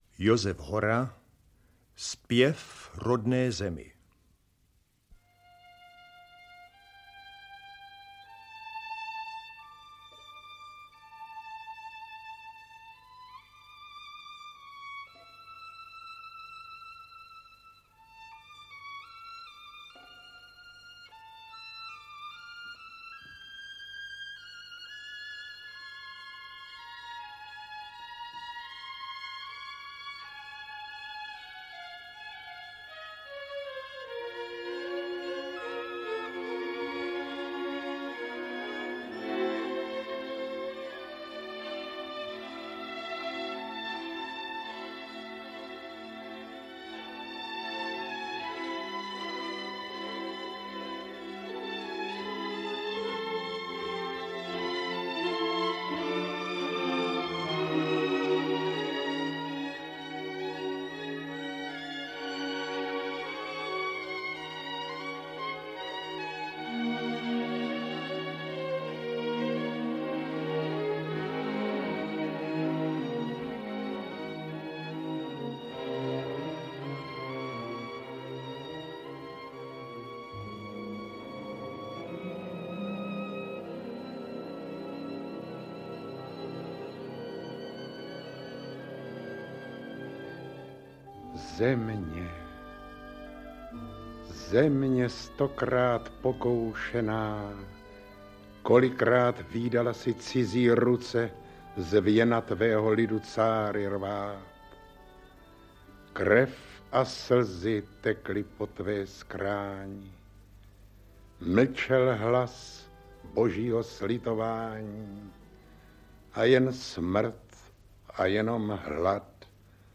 Padesát let republiky. Pásmo veršů k 50. výročí vzniku československého státu - Josef Hora - Audiokniha
• Čte: Zdeněk Štěpánek